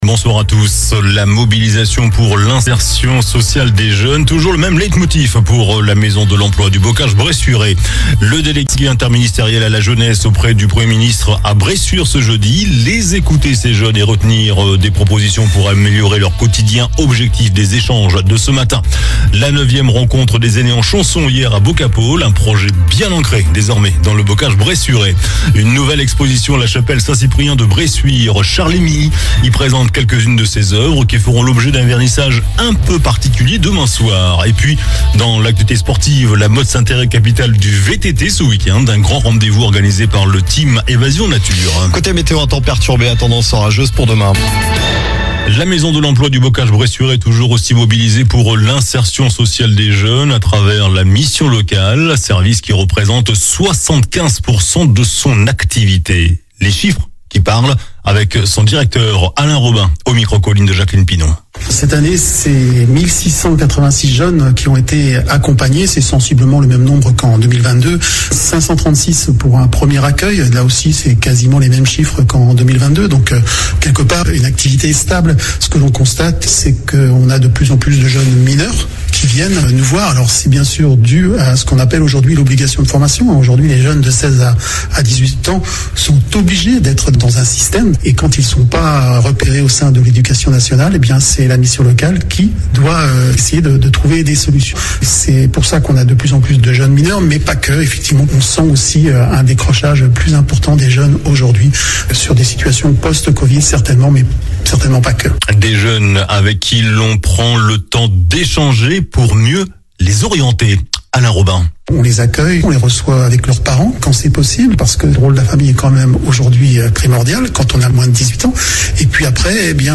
JOURNAL DU JEUDI 16 MAI ( SOIR )